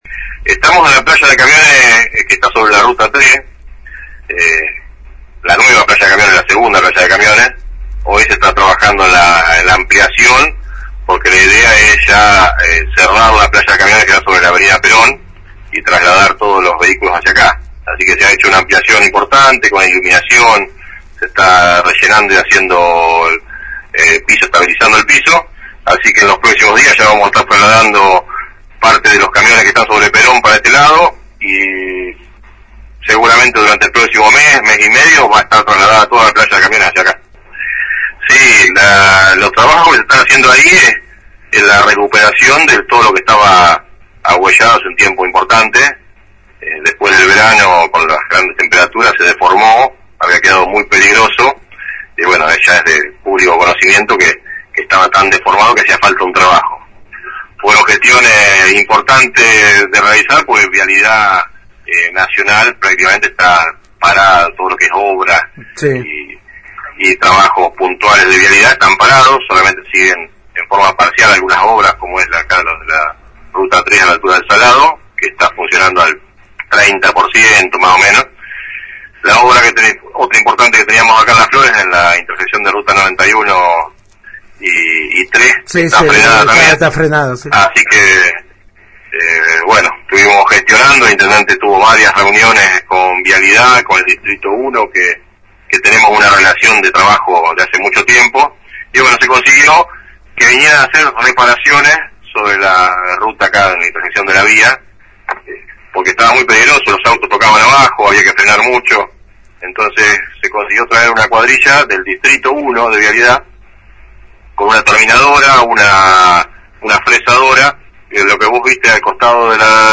(foto archivo) El secretario de Obras, Planeamiento y Servicios Públicos, habló con la 91.5 sobre los últimos trabajos en la nueva planta ubicada sobre ruta 3.